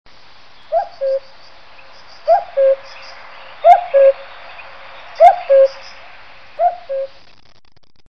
cuco.wav